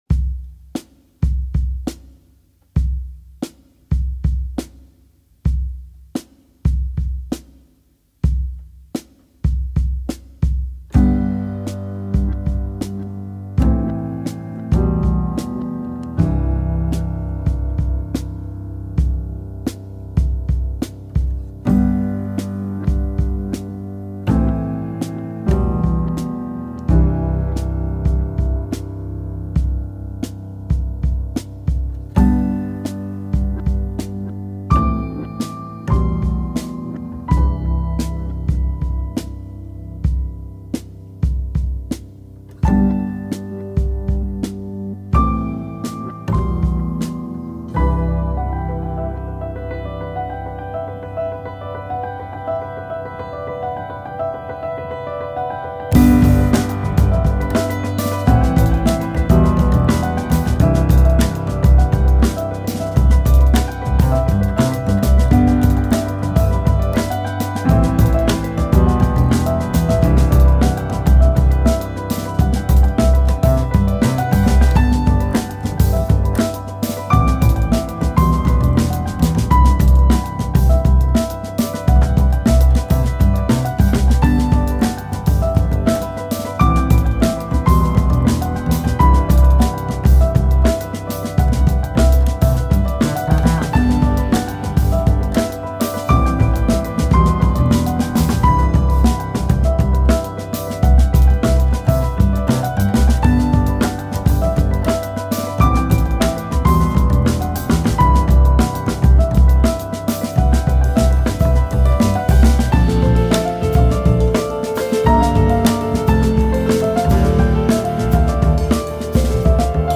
21st-century jazz group
The slow, simple beginning reminds me of Ahmad Jamal.